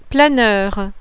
PLANEUR.wav